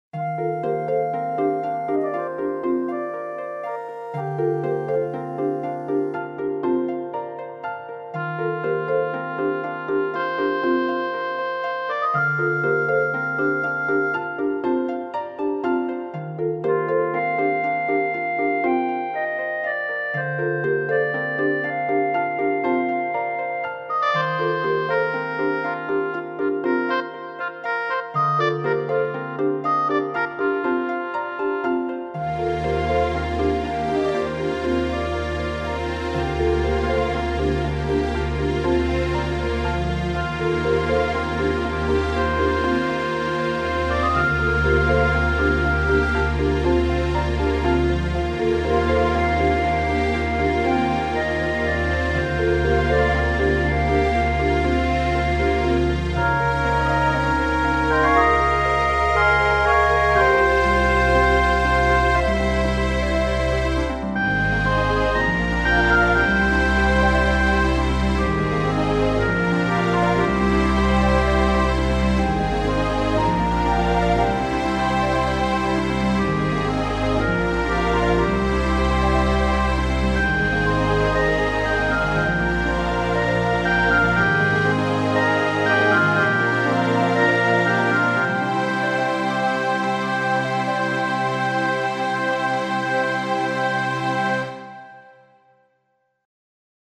浮遊感の漂う曲。